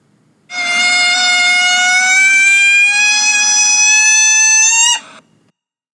Play Balloon Squeal - SoundBoardGuy
Play, download and share Balloon Squeal original sound button!!!!
balloon-squeal.mp3